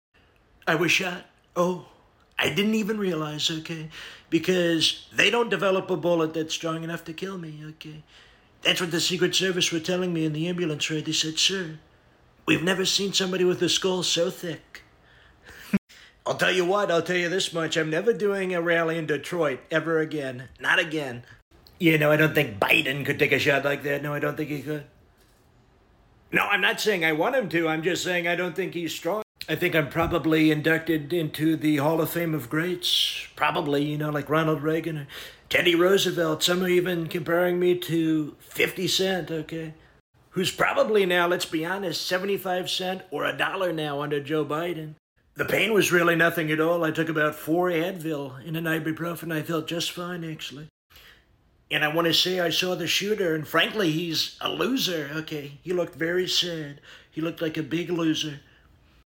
1 minute audio of Trump interview today.